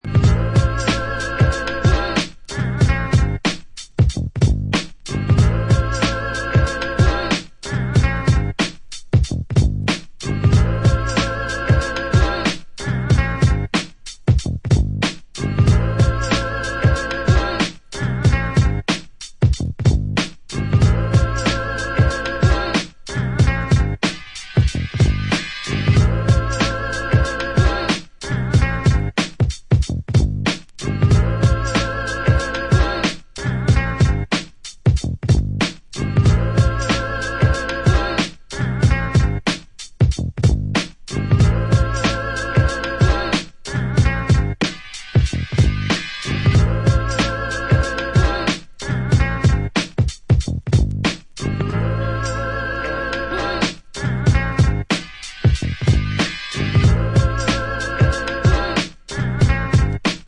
instrumentals on triple vinyl